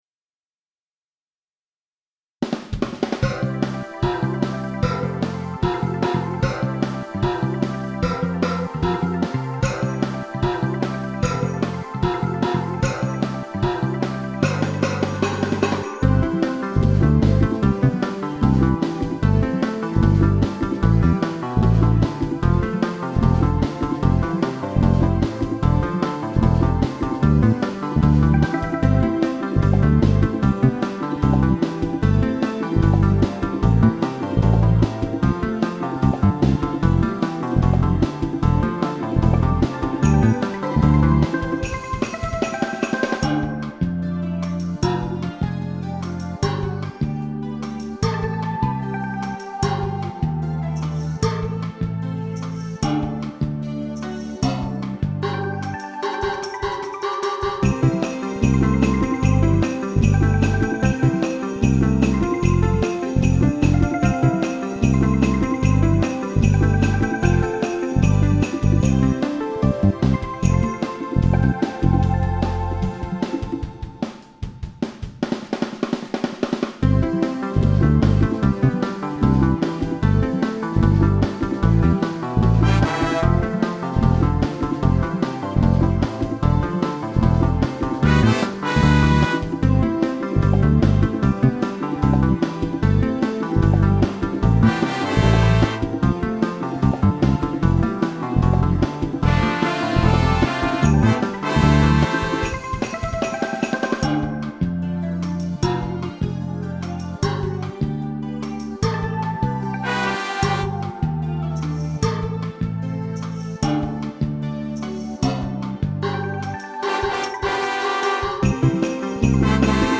カラオケ(mp3)
maketan_full_karaoke.mp3